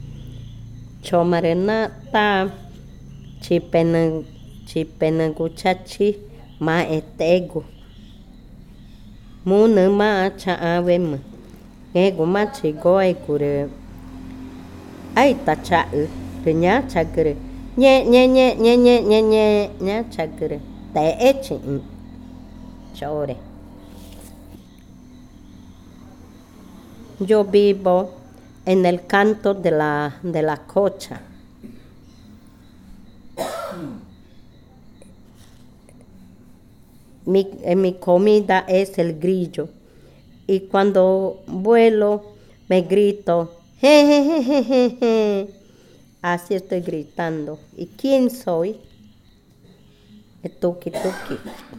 Cushillococha